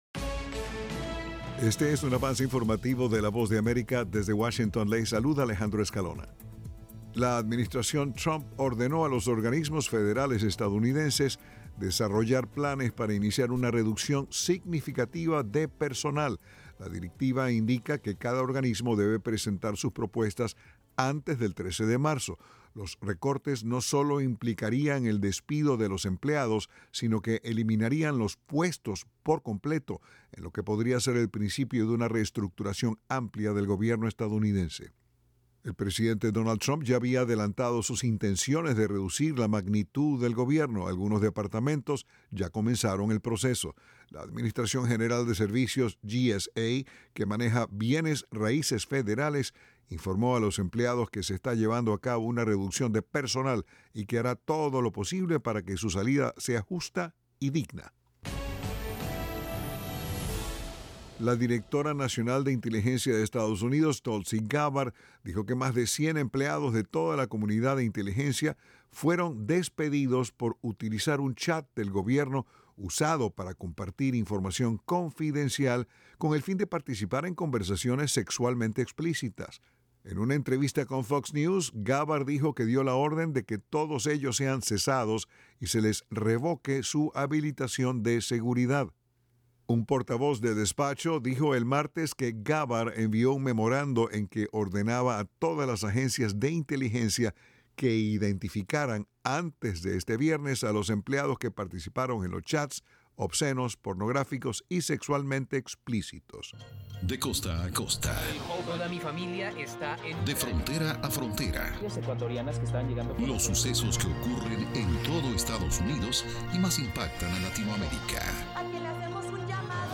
El siguiente es un avance informativo de la Voz de América.
["Avance Informativo" es un segmento de noticias de la Voz de América para nuestras afiliadas en la región de América Latina y el Caribe].